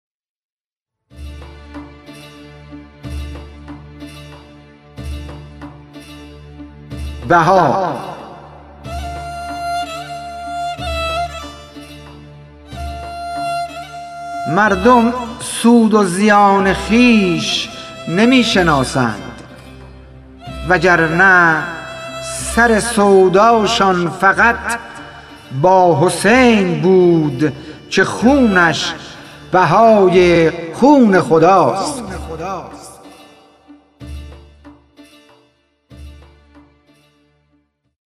خوانش شعر سپید عاشورایی / ۲